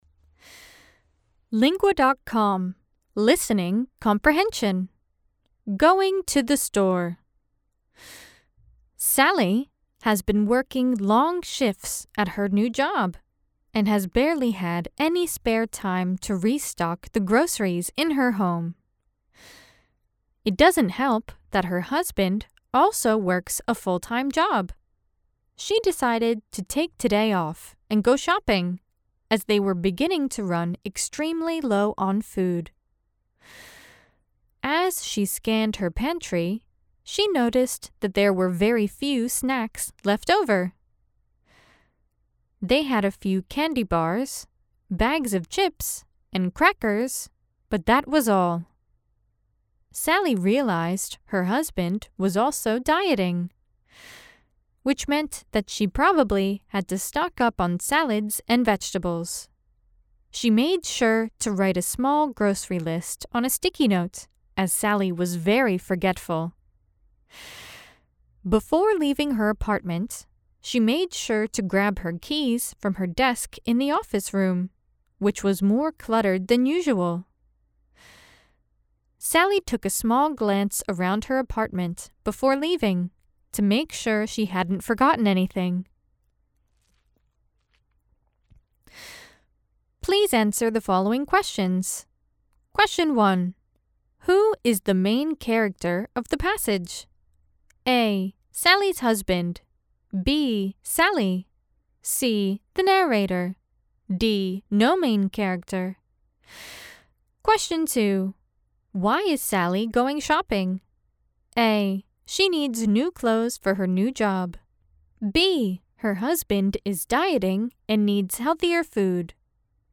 Estados Unidos